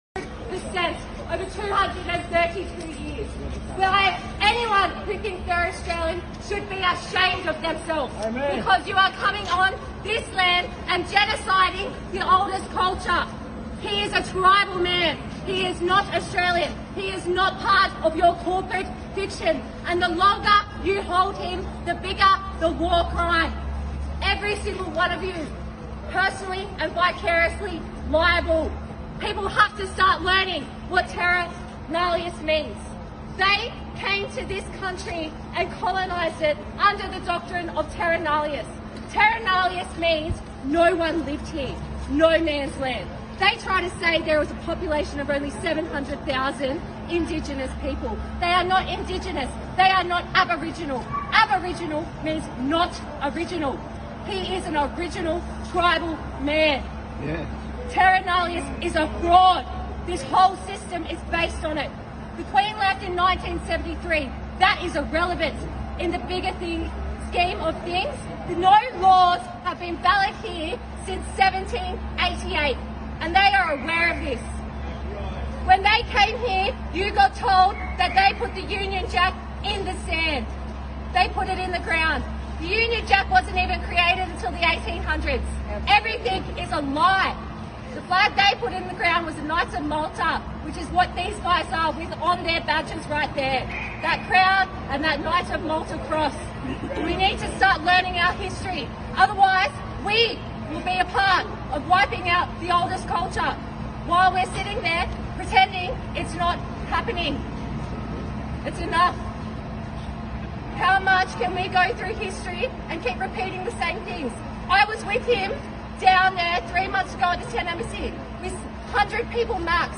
האוסטרלים מתעוררים - תקשיבו לאשה המדהימה הזאת ושתפו